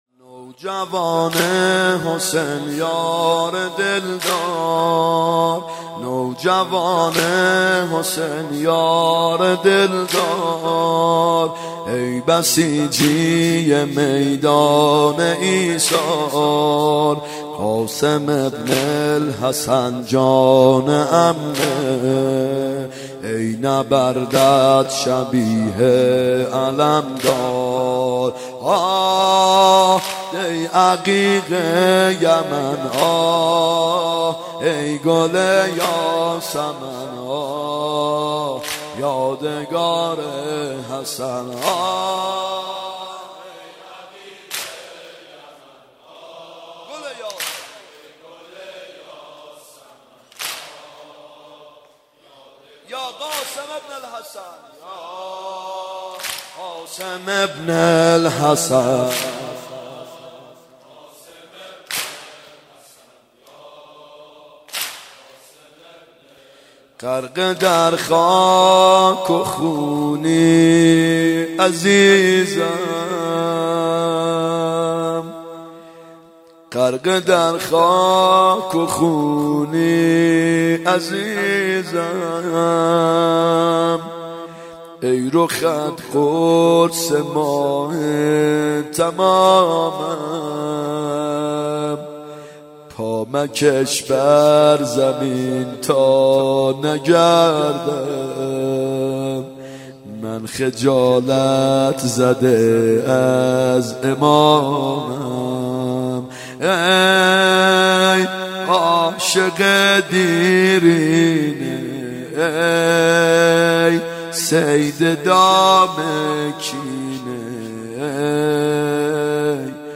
محرم 92 شب ششم واحد
محرم 92 ( هیأت یامهدی عج)